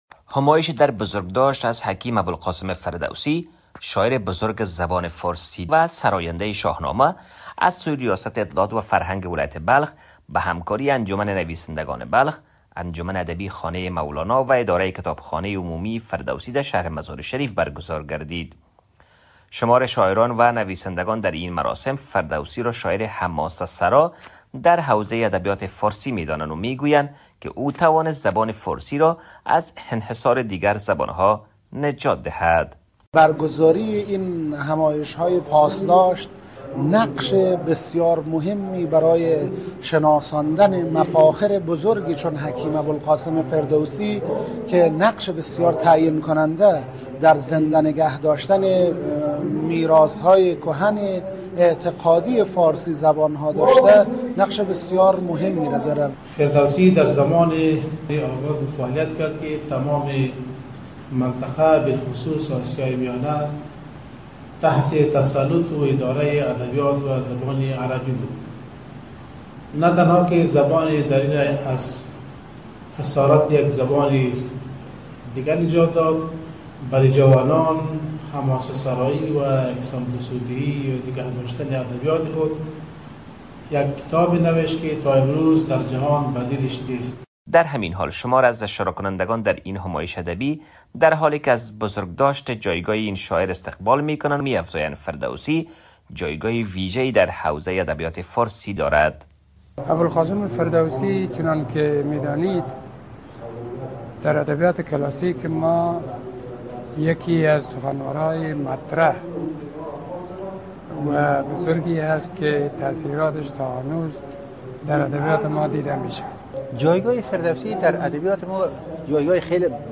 همایش بزرگداشت حکیم فردوسی با حضور شعرا، نویسندگان و علاقمندان شعر و ادب فارسی در کتابخانه فردوسی مزار شریف برگزار شد.